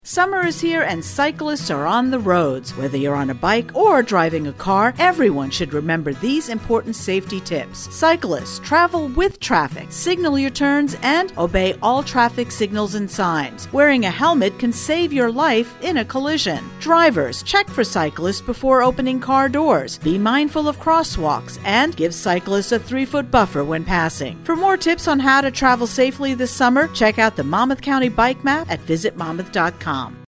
The Monmouth County Transportation Council recently released a public service announcement to remind the public to practice bike safety. Listen to the Transportation Council's PSA